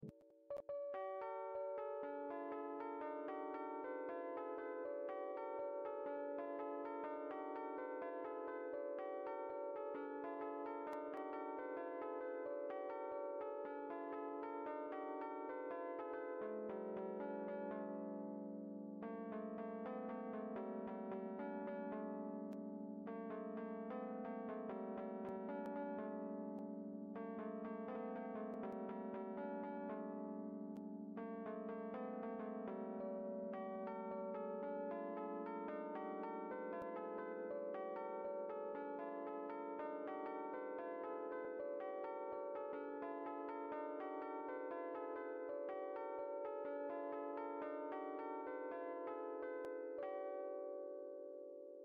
I’d say work a bit on note timing but other than that it sounds really good